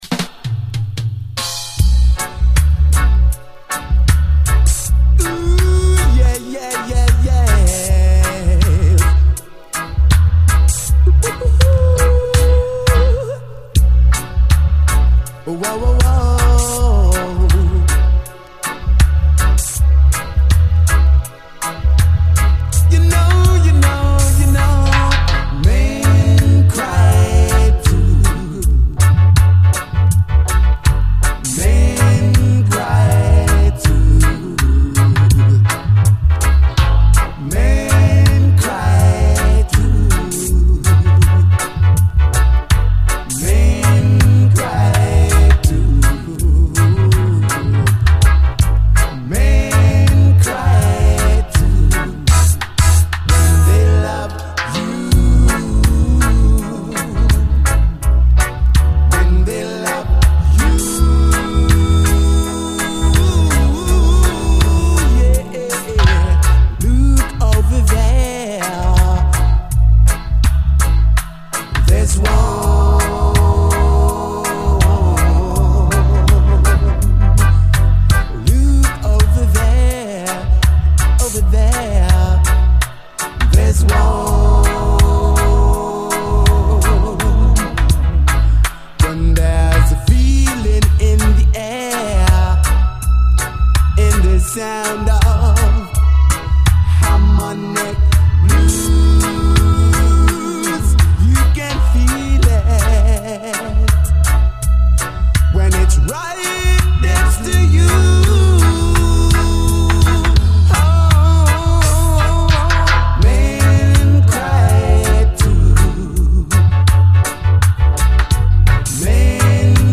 REGGAE
最高の哀愁系80’Sメール・ラヴァーズ！
盤スレ見られますが実際は概ね綺麗に聴けます
心地よいコーラス・ハーモニーやバッキングの細やかなシンセも含め、繊細な男の泣き言系のグレイト・トラック！後半にはダブも。